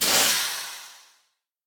train-breaks-2.ogg